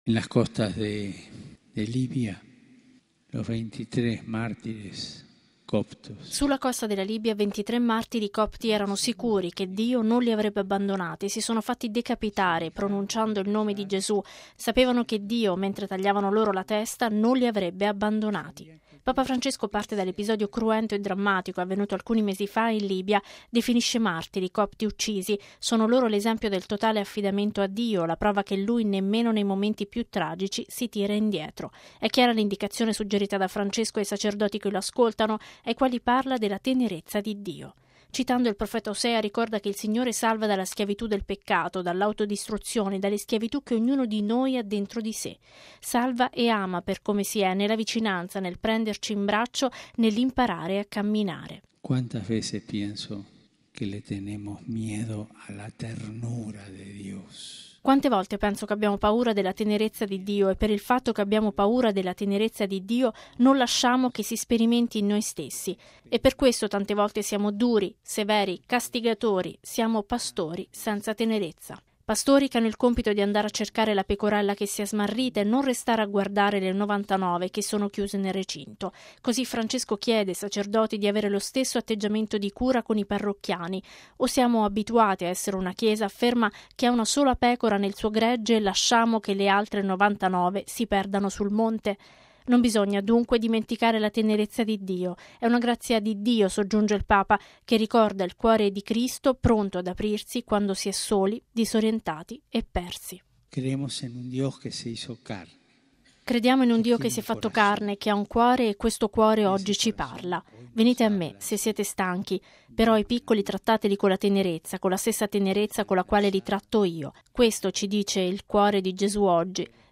Il Pontefice ha anche ricordato i cristiani copti decapitati da miliziani dello Stato islamico. Il servizio